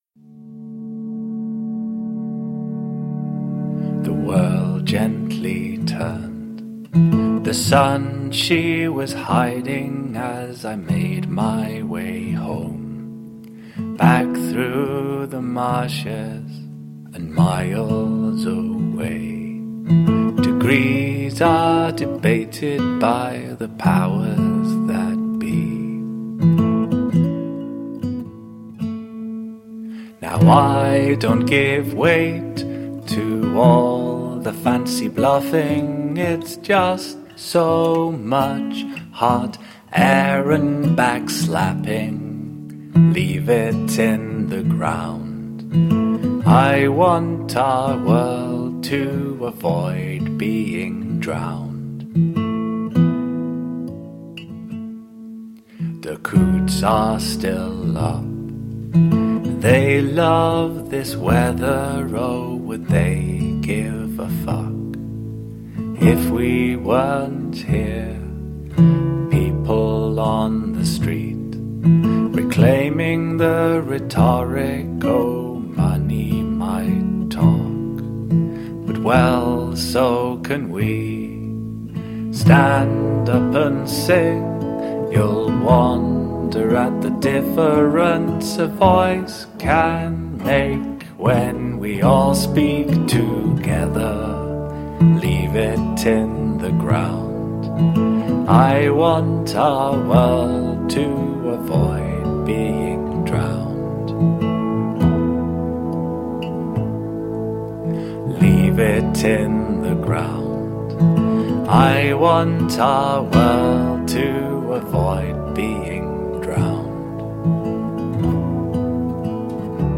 Gently experimental nu-folk.
Tagged as: Alt Rock, Folk-Rock, Folk